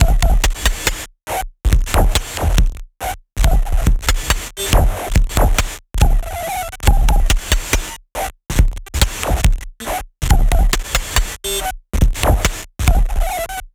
Abstract Rhythm 16.wav